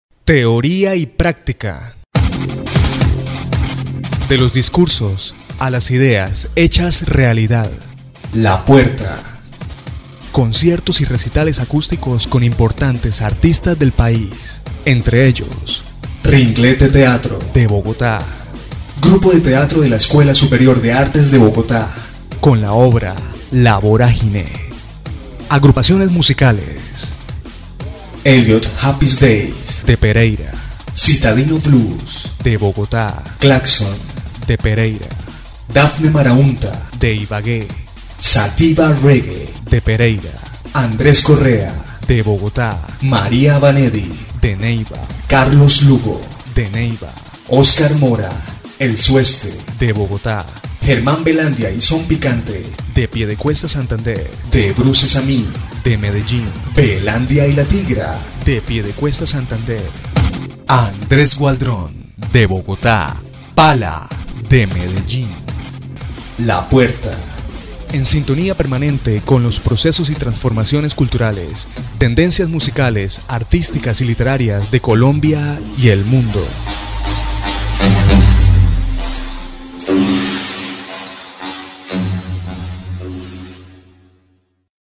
kolumbianisch
Sprechprobe: Industrie (Muttersprache):